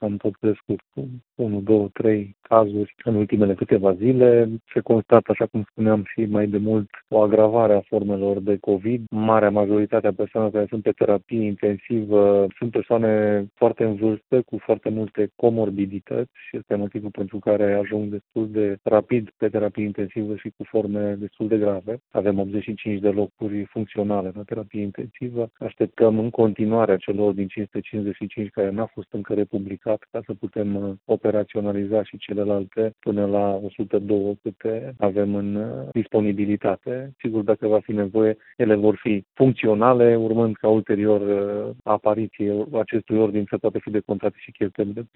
Suplimentarea cu încă aproape 20 de paturi ATI așteaptă o reglementare de la nivel național, pentru a permite decontarea cheltuielilor spitalelor, a spus prefectul Mircea Abrudean, pe care îl puteți asculta aici: